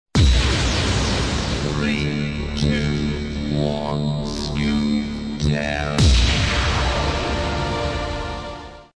Kermis Jingle's  2013
Countdown
Jingle-18-Countdown-.mp3